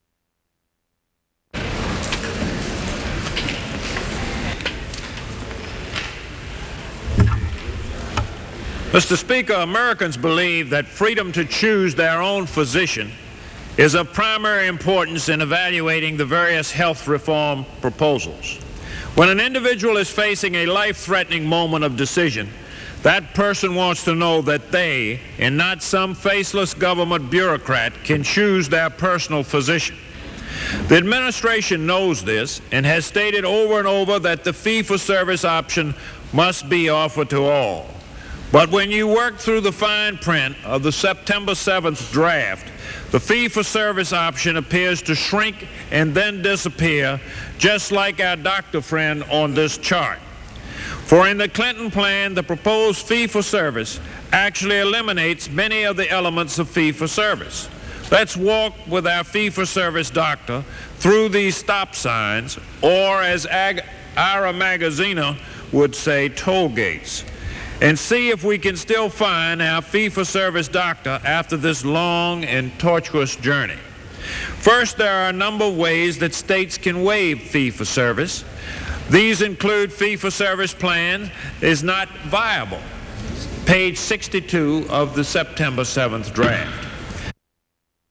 U.S. Representative Thomas Bliley (R-VA) rises to insist on personal choice of physician and fee-for-service included in any medical reform program
Broadcast on C-SPAN, Oct. 21, 1993.